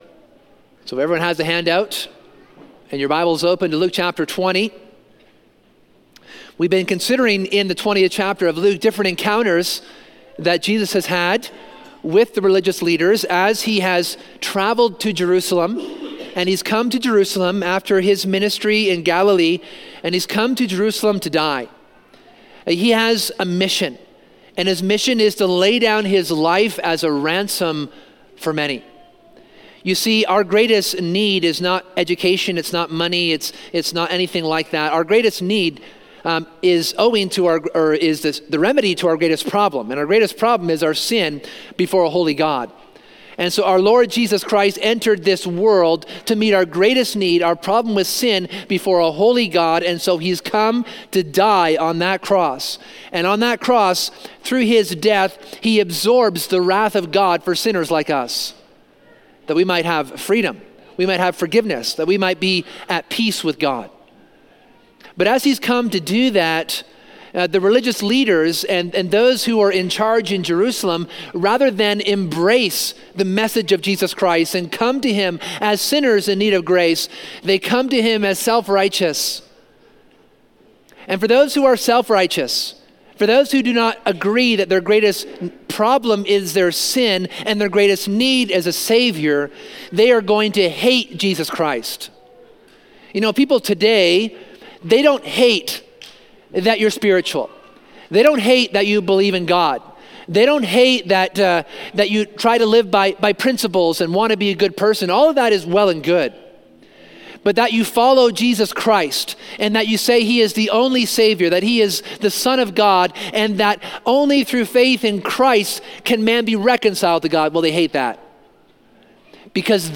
This sermon considers the end of Jesus’ interactions with the scribes.